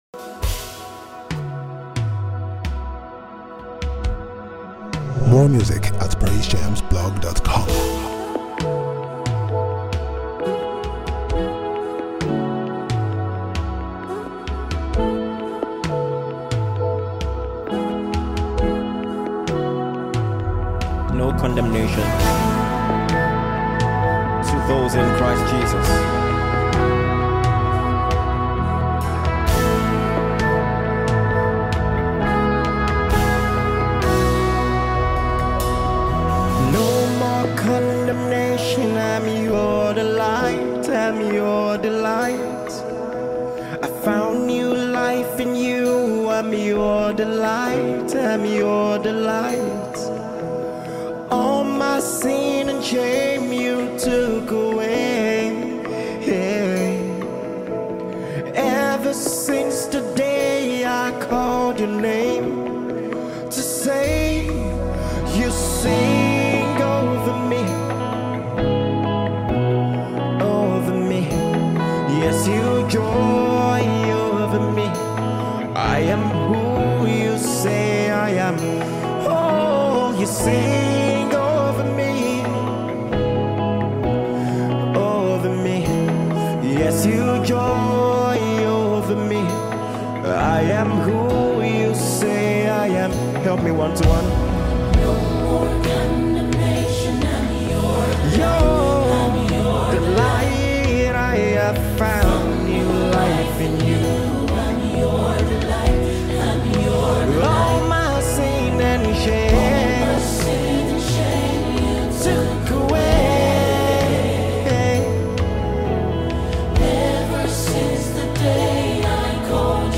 gospel
heartfelt vocals